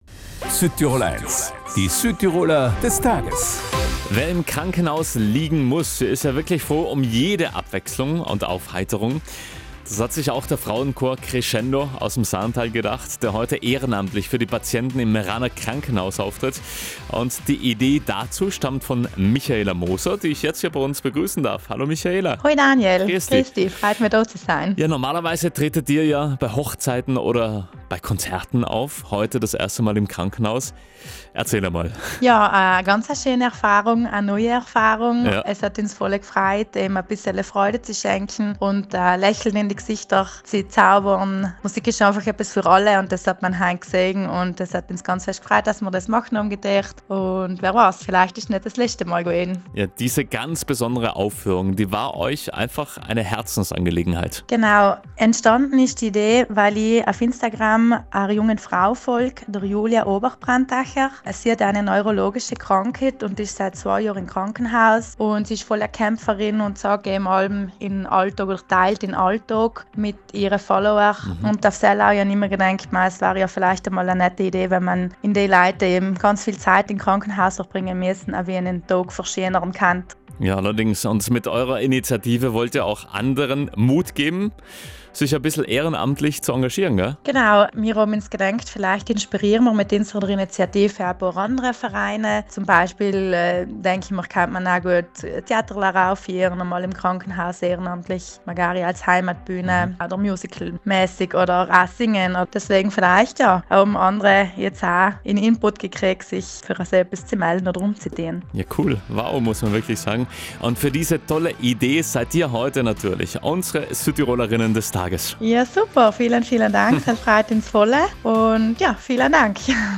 Normalerweise tritt der Frauenchor Crescendo aus dem Sarntal bei Hochzeiten oder Konzerten auf – diesmal aber sangen die 22 Hobbysängerinnen im Meraner Krankenhaus. Mit ihrer Musik wollten sie den Patienten Freude schenken und für Abwechslung sorgen.
SDT_Frauenchor.WAV